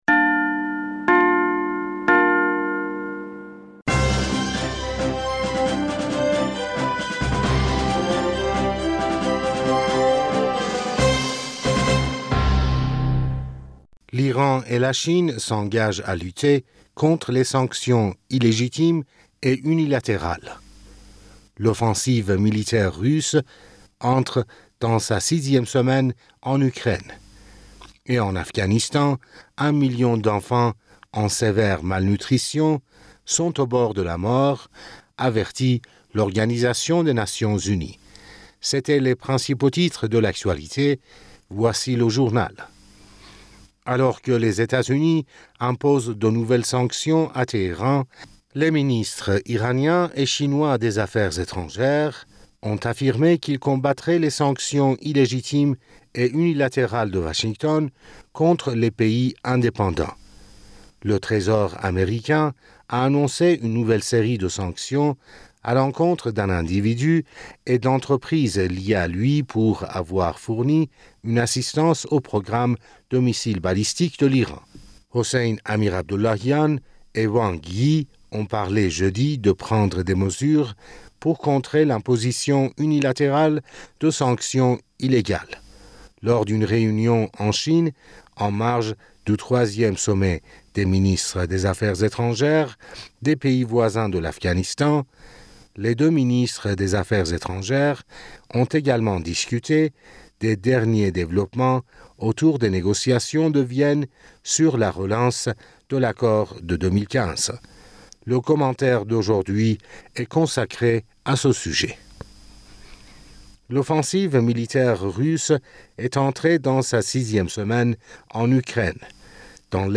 Bulletin d'information Du 01 Avril 2022